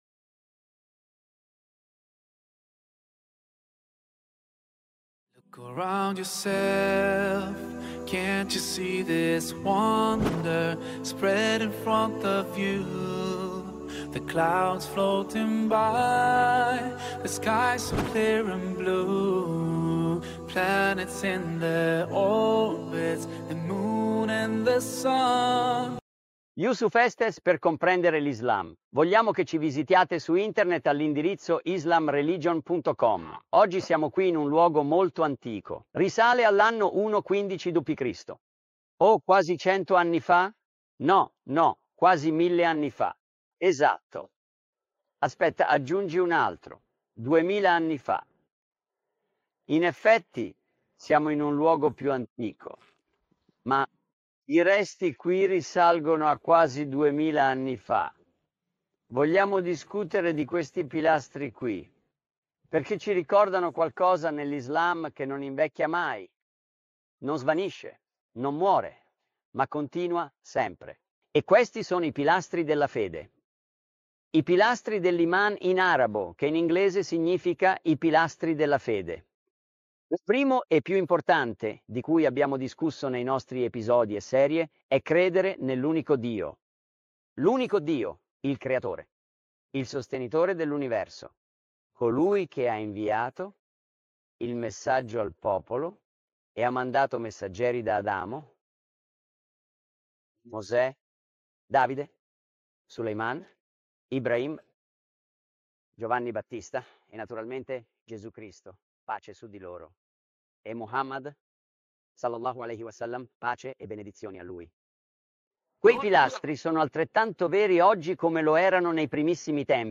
filmed in the backdrop of scenic landscapes and historic places of Jordan. In this episode, he explains all the pillars of faith--belief in God, Angels, Prophets, Scriptures, Judgment Day and divine decree.